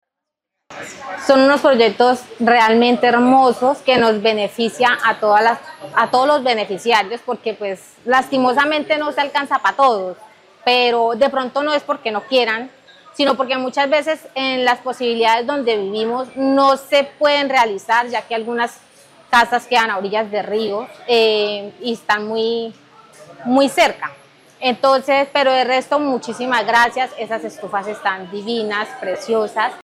beneficiaria del municipio de La Dorada.